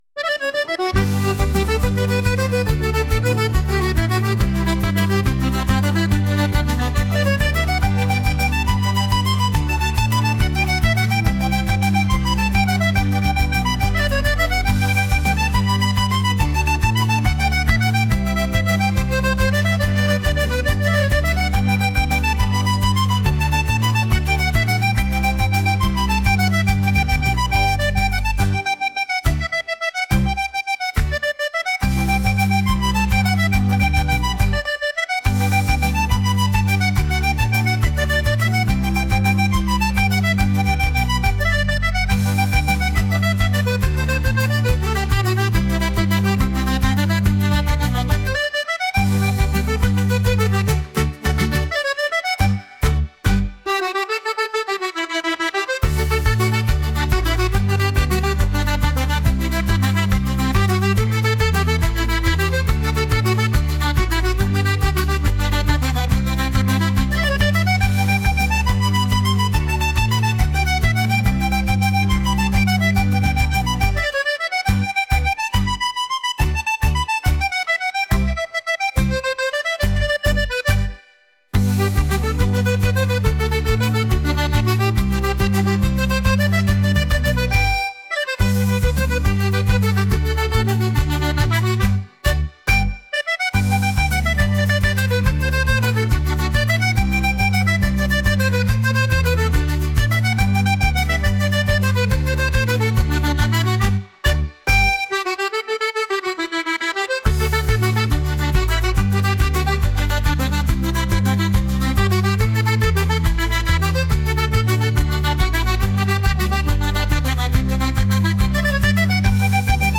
energetic | traditional | world